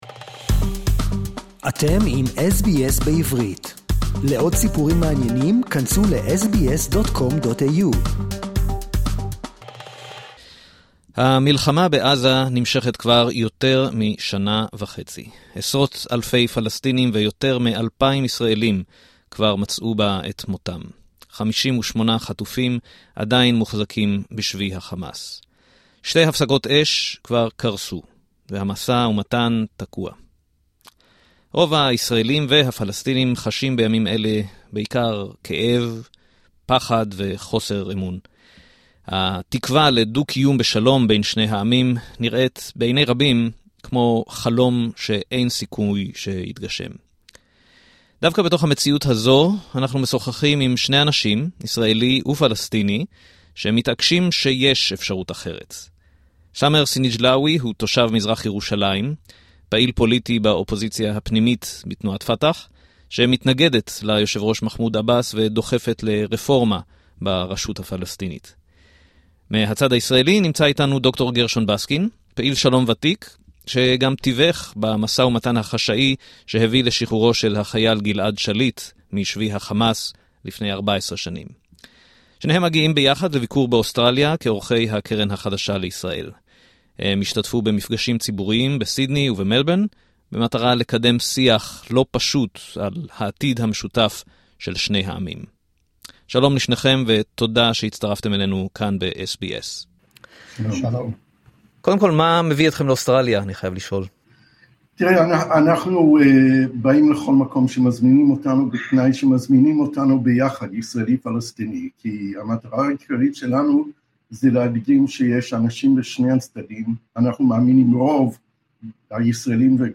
מה יש לשני פעילי שלום - ישראלי ולפלסטיני - לומר לנו דווקא עכשיו בימים של מלחמה וייאוש?